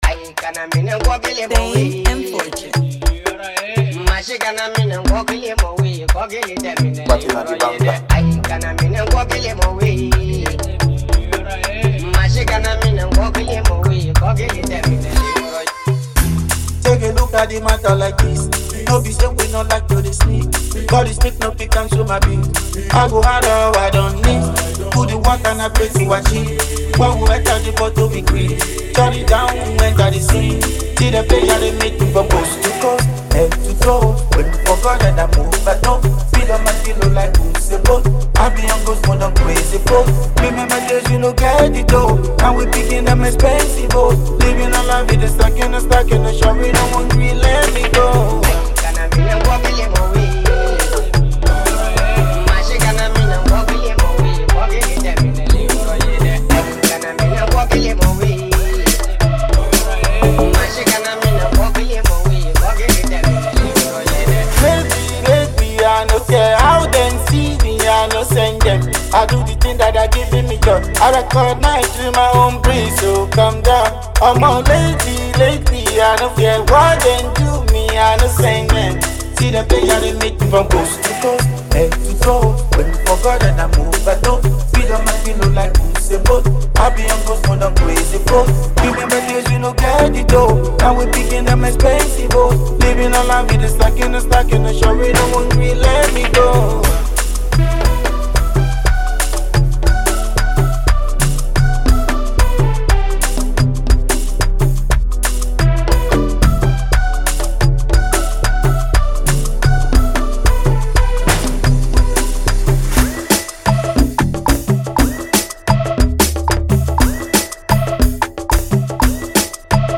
high-energy single